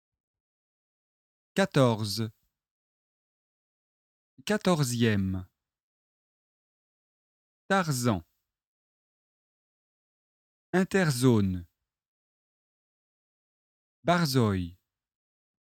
🎧 Exercice 14 : écoutez et répétez.
[ r ] + [ z ]